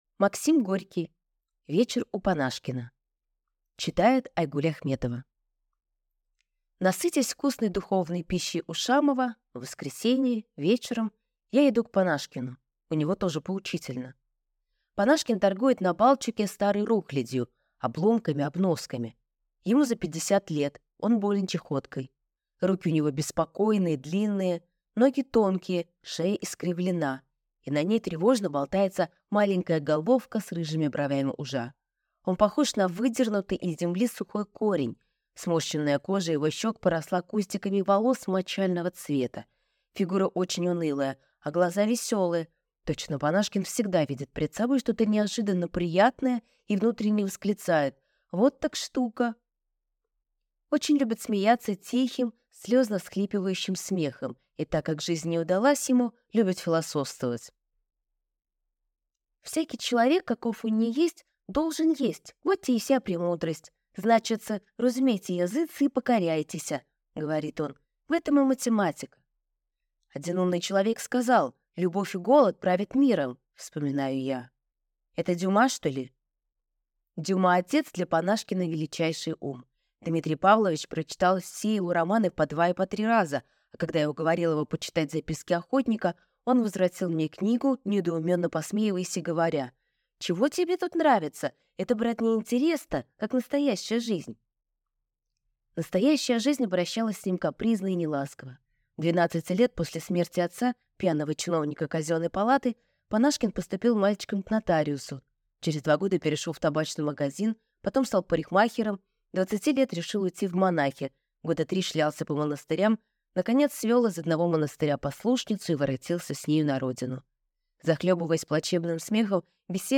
Аудиокнига Вечер у Панашкина | Библиотека аудиокниг